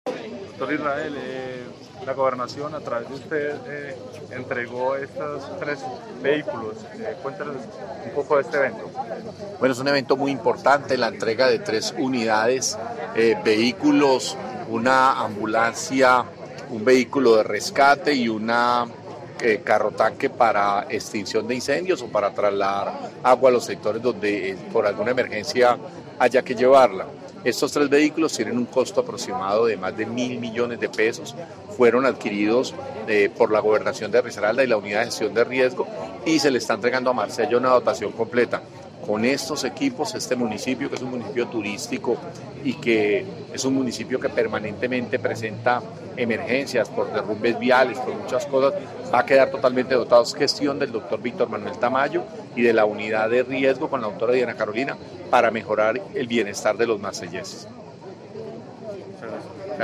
Escuchar Audio: Israel Londoño, Secretario de Gobierno de Risaralda.